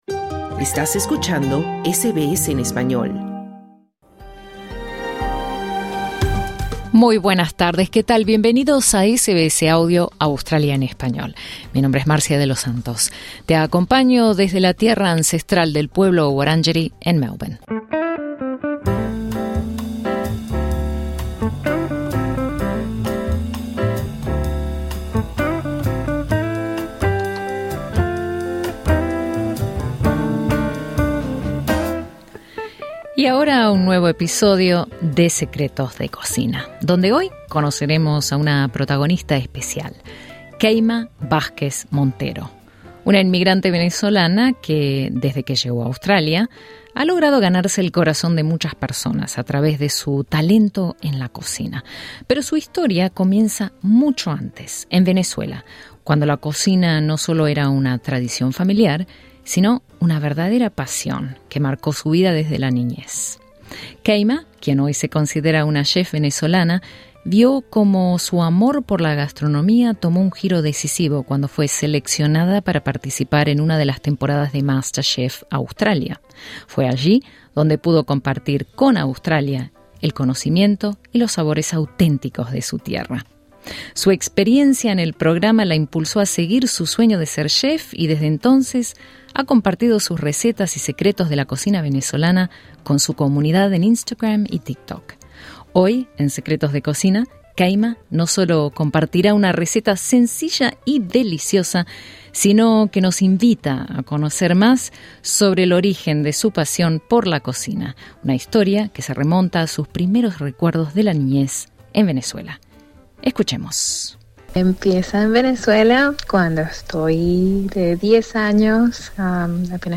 Conversamos con la migrante venezolana para conocer más sobre el origen de su pasión por la cocina, una historia que se remonta a sus primeros recuerdos de la niñez en Venezuela.